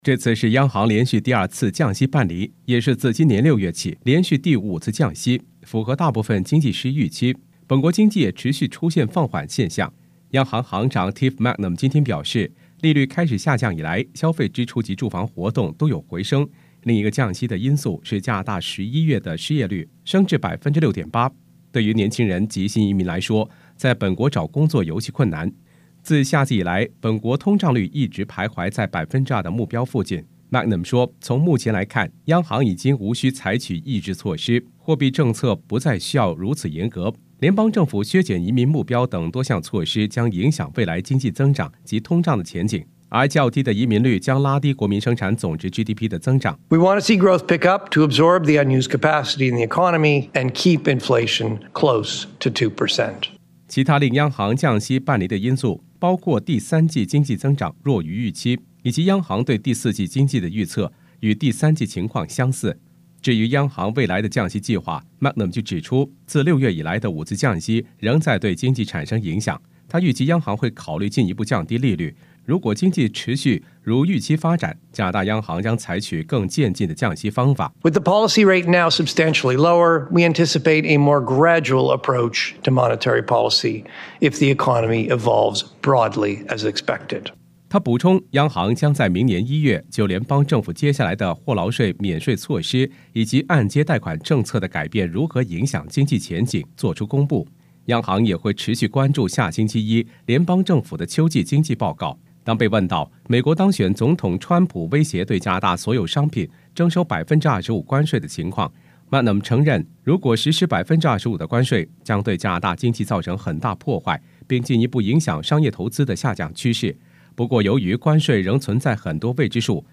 news_clip_21670_mand.mp3